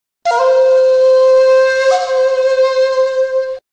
Sound Buttons: Sound Buttons View : Ninja Sounds
ninja-sound-effect.mp3